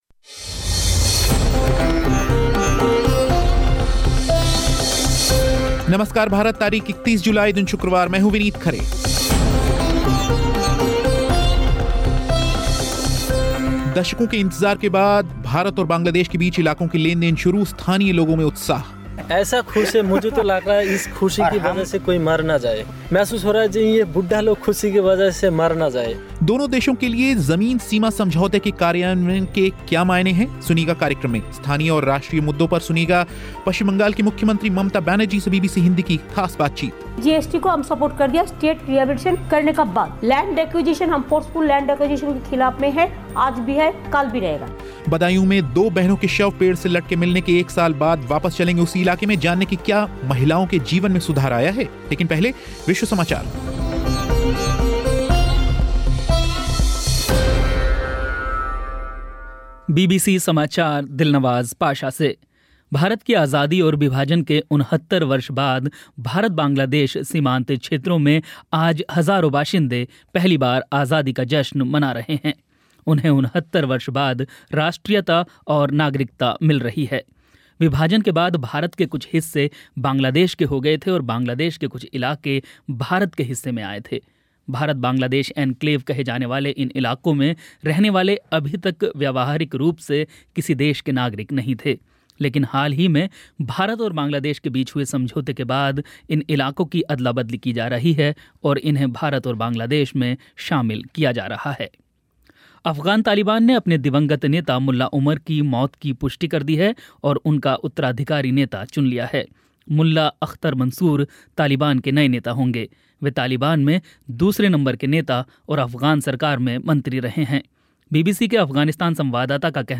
स्थानीय लोगों में उत्साह. दोनो देशों के लिए ज़मीन सीमा समझौते के कार्यान्यवयन के क्या मायने हैं, सुनिएगा कार्यक्रम में. स्थानीय और राष्ट्रीय मुद्दों पर सुनिएगा पश्चिम बंगाल की मुख्यमंत्री ममता बैनर्जी से बीबीसी हिंदी की खास बातचीत. बदांयू में दो बहनों के शव पेड़ से लटके मिलने के एक साल बाद वापस चलेंगे उसी इलाके में, जानने कि क्या महिलाओं के जीवन में सुधार आया है.